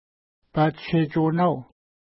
Pronunciation: pa:ttʃetʃu:na:w